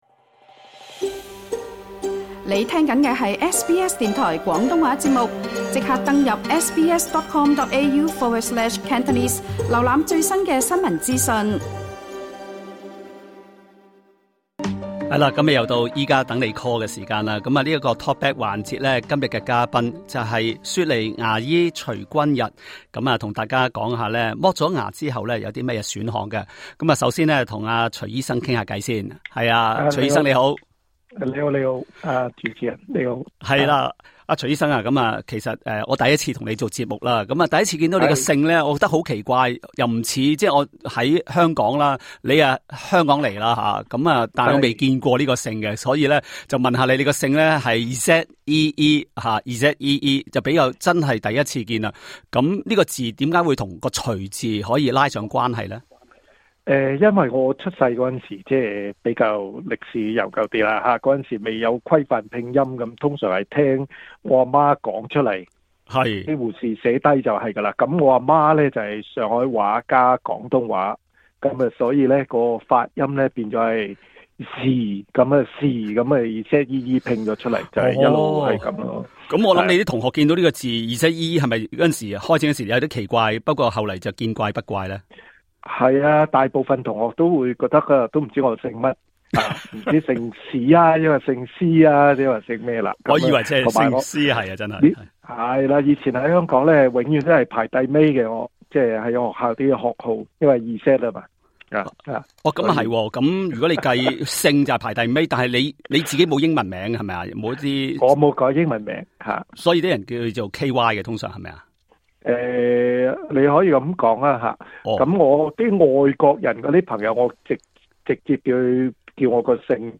在本集《醫家等你Call》talkback 環節